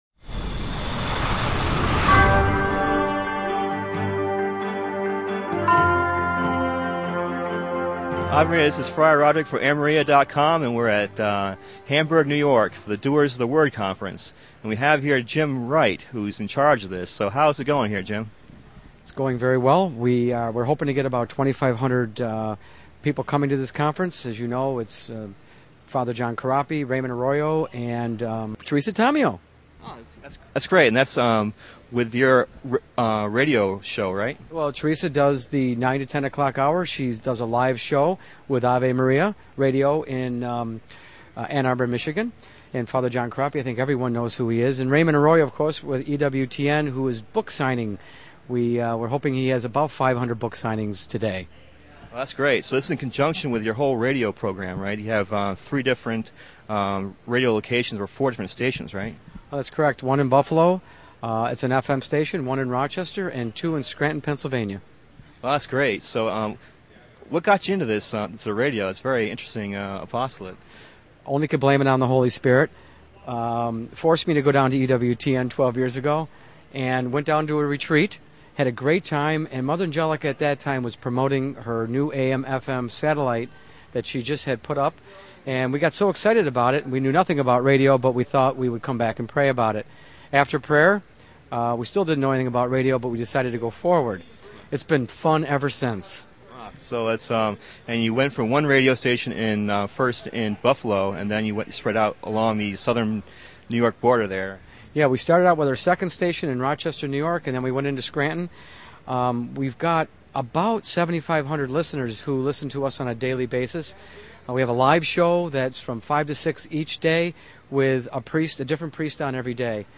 Roving Reporter #3 - Station of the Cross Catholic Radio Station, in Buffalo, NY >>> Play Ave Maria!